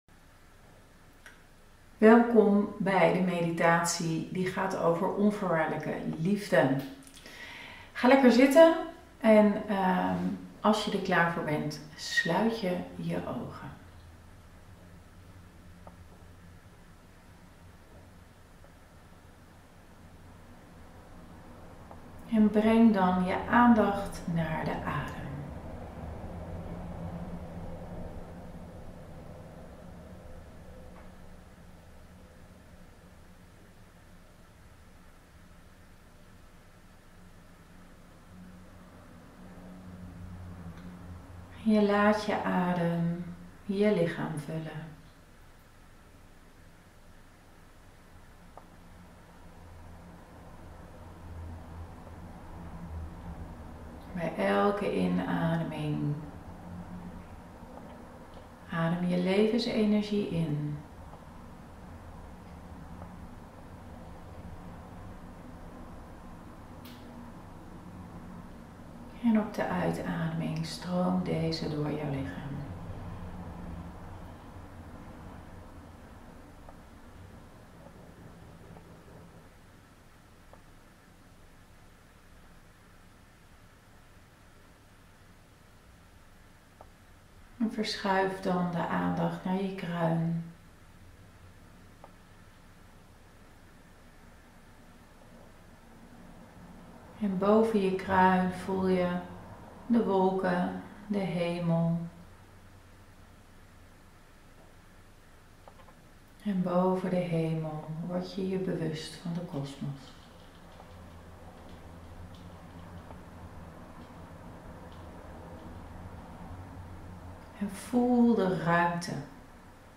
5.1 Meditatie: Onvoorwaardelijke liefde